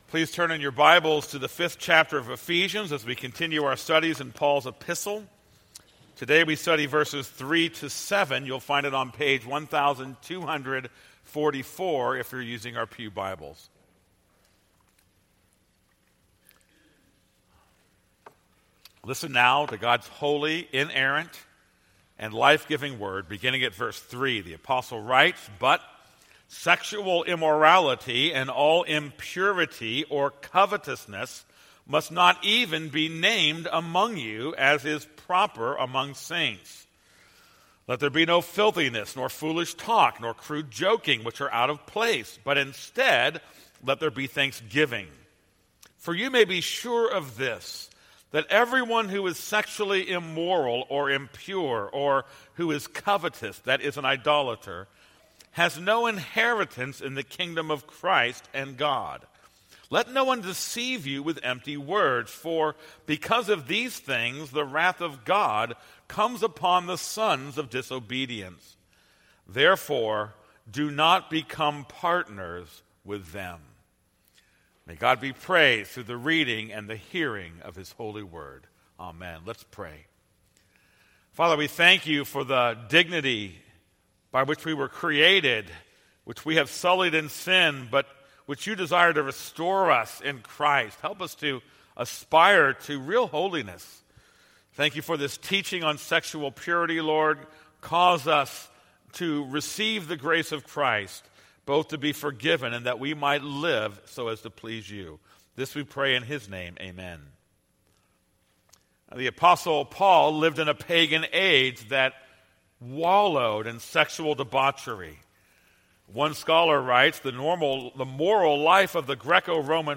This is a sermon on Ephesians 5:3-7.